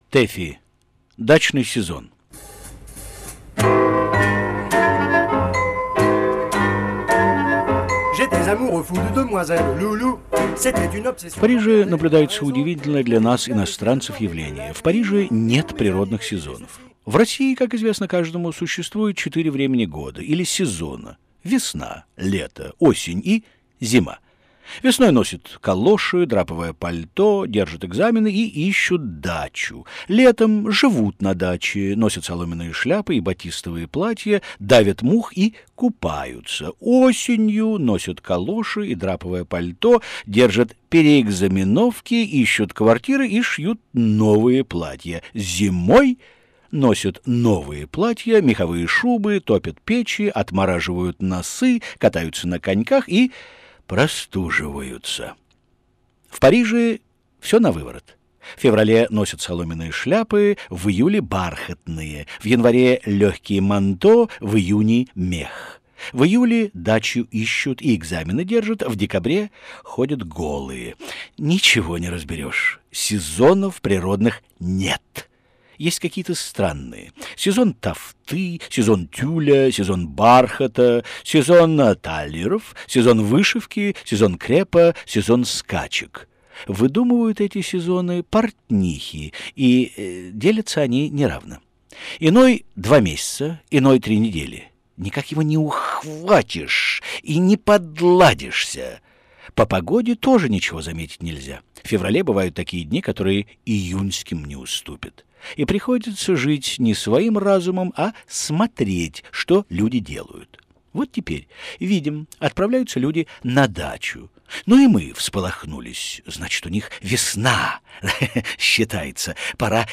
в прочтении